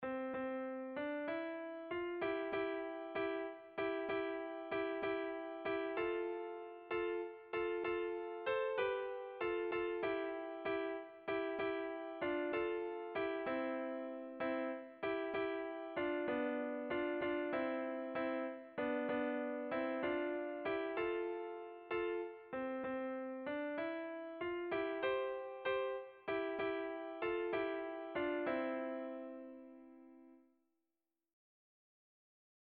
Tragikoa
Zortziko txikia (hg) / Lau puntuko txikia (ip)
ABDA2